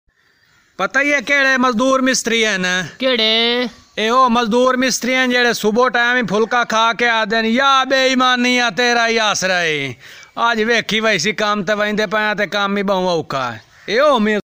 You Just Search Sound Effects And Download. tiktok funny sound hahaha Download Sound Effect Home